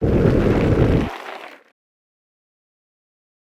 Toon flamethrower.wav